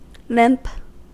Ääntäminen
US : IPA : [lɪmp]